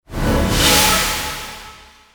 / F｜演出・アニメ・心理 / F-03 ｜ワンポイント1_エフェクティブ
合成エフェクト 01
ウーシャー